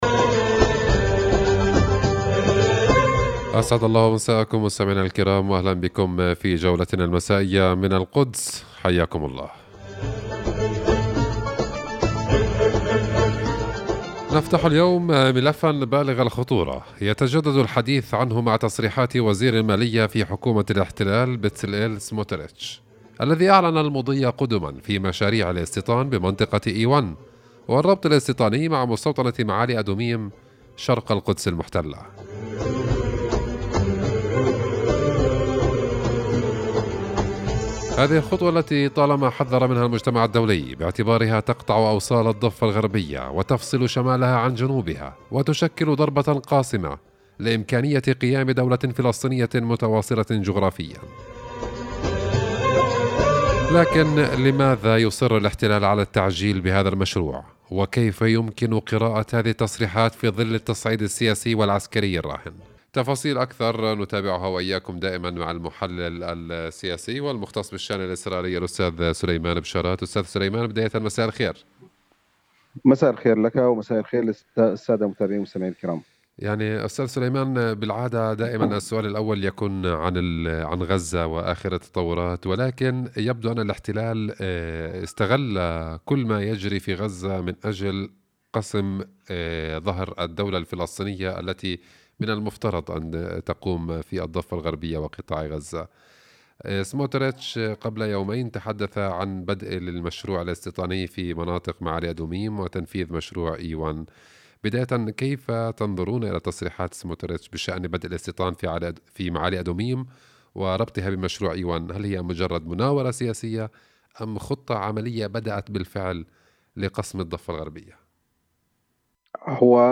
حديث إذاعي